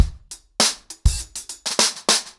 DrumkitRavage-100BPM_1.5.wav